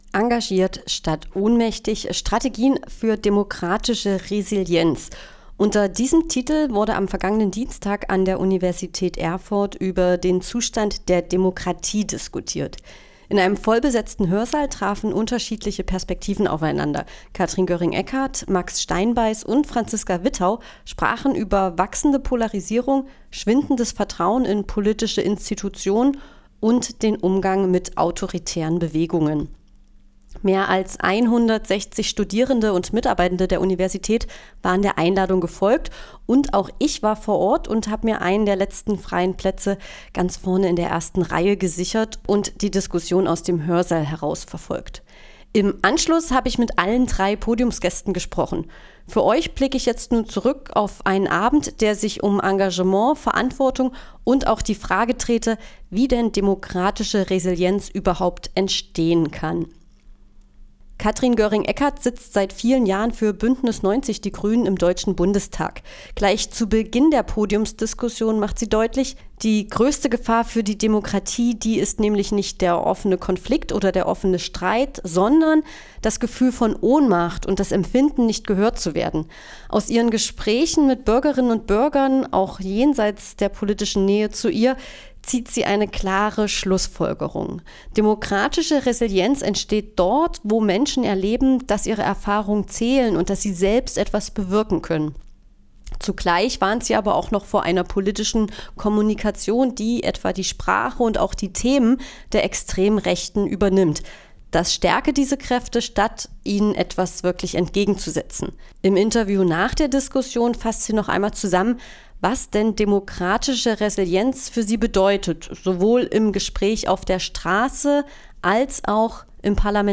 Im Anschluss an die Diskussion hat Radio F.R.E.I. mit den drei Redner:innen gesprochen.